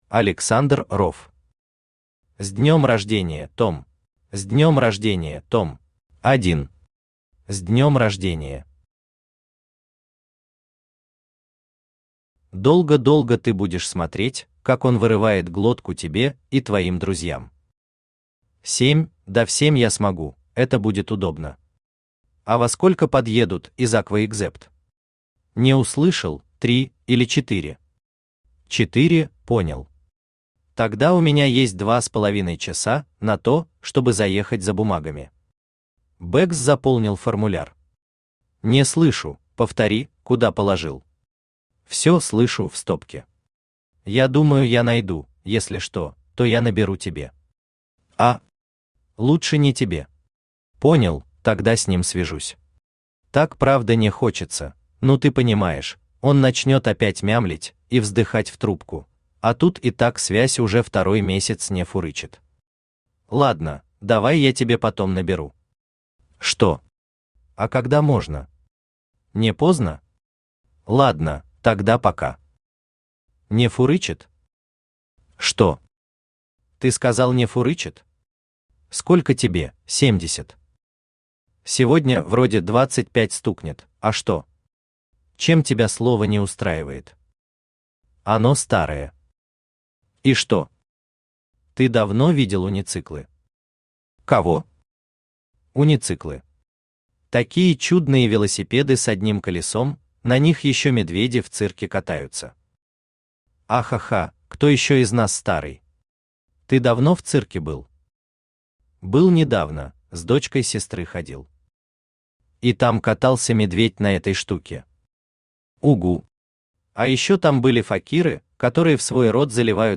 Автор Александр Ров Читает аудиокнигу Авточтец ЛитРес.